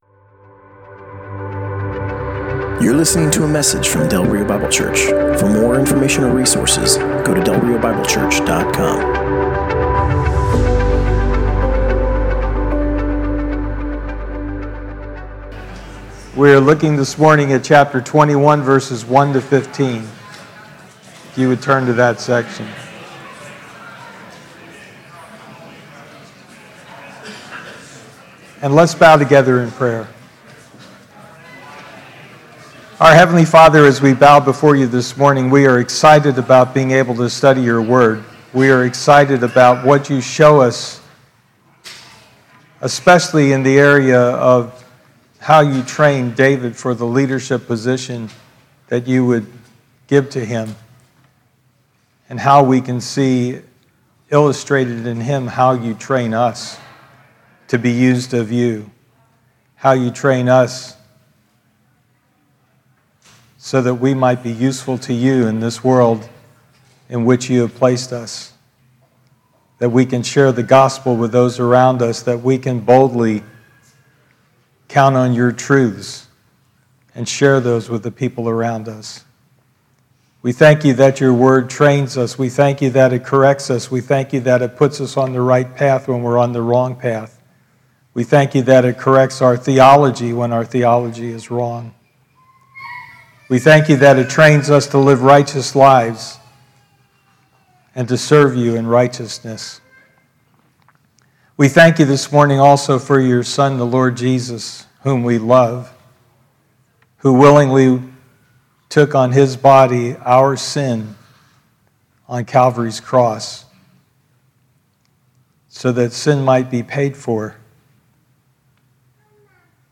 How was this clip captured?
Passage: 1 Samuel 21: 1-15 Service Type: Sunday Morning